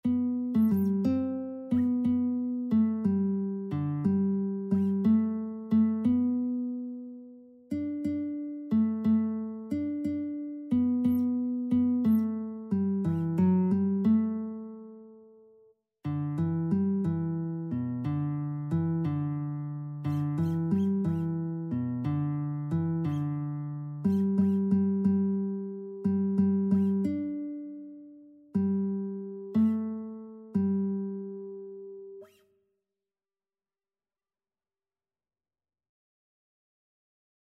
Christian
6/8 (View more 6/8 Music)
Classical (View more Classical Lead Sheets Music)